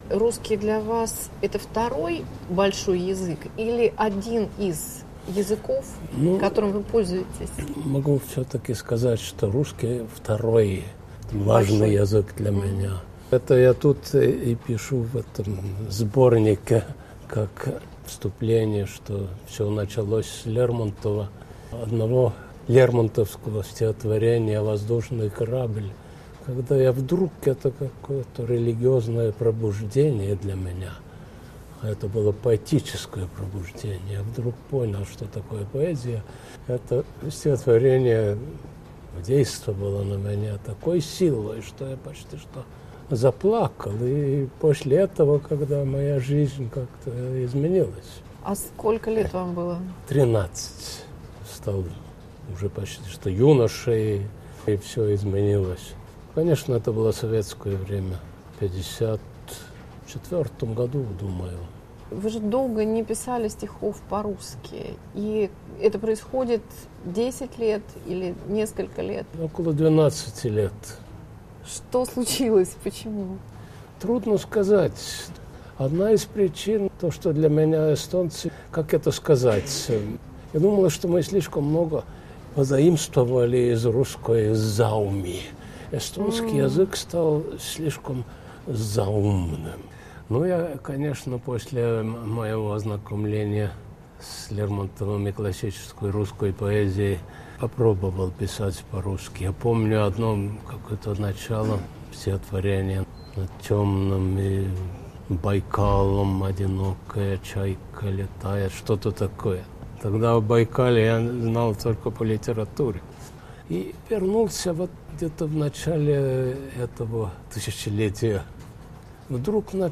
Разговор с Яном Каплинским в программе "Культурный дневник"